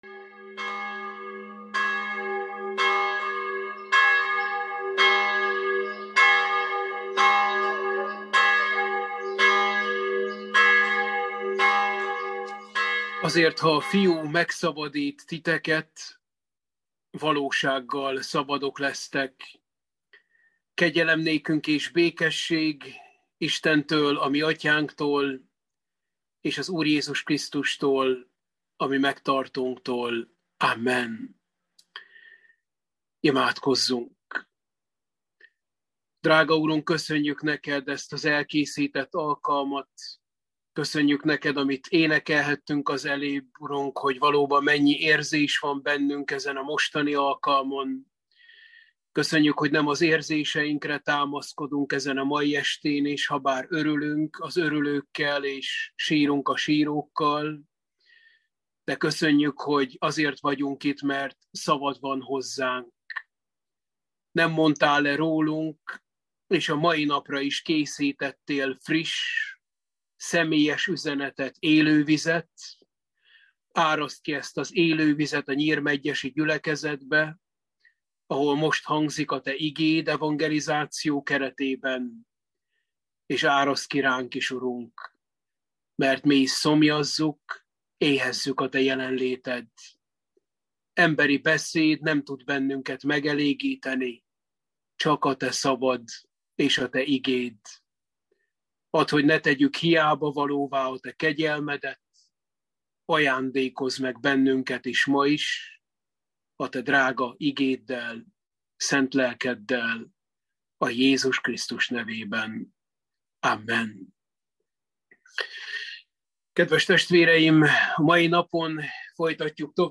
Efézusi levél – Bibliaóra 6